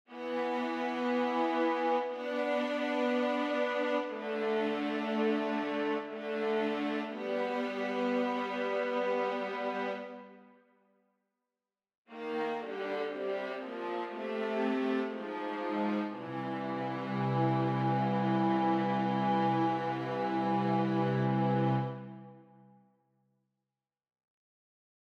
Key written in: C Major
Type: Barbershop